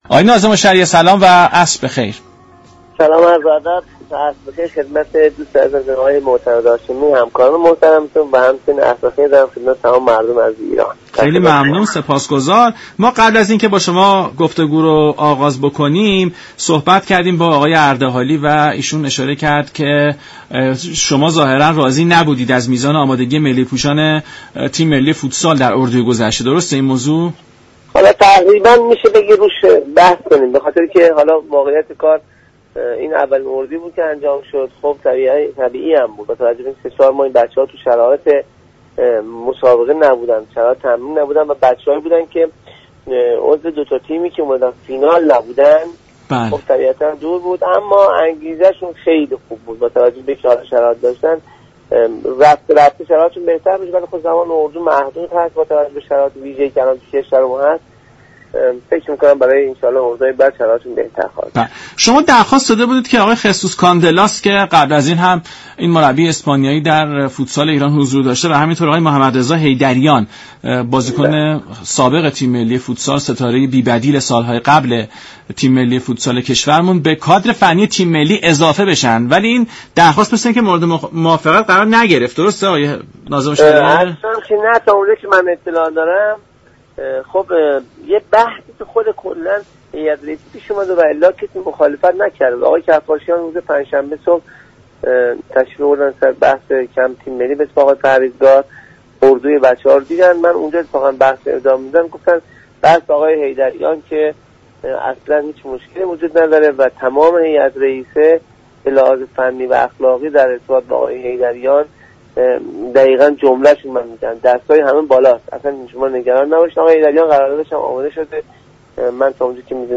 سرمربی تیم ملی فوتسال در رادیو ایران: كرونا زمان اردوها را محدود كرده است